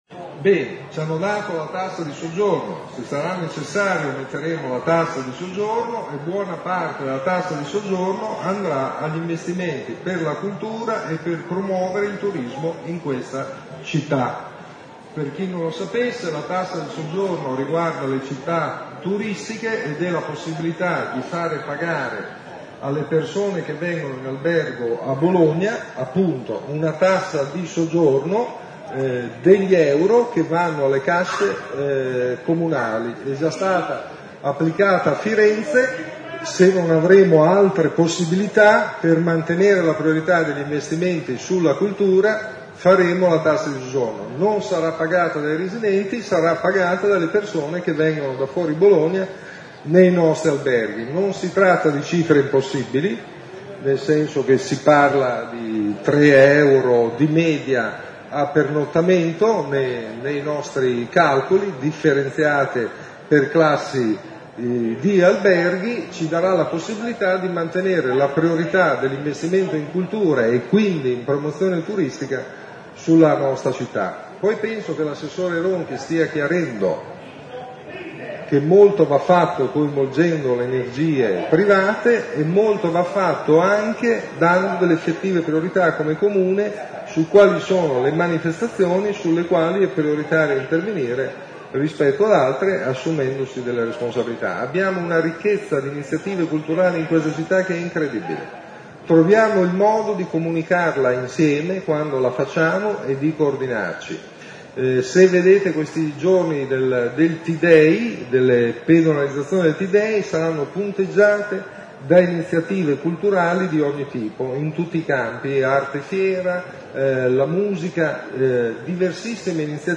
E’ questo il messaggio che arriva dalla festa dell’Unità dove il sindaco ha parlato a ruota libera davanti al pienone della sala dibattiti centrale.
Ascolta il sindaco che parla della tassa di soggiorno
merola-su-tassa-soggiorno.mp3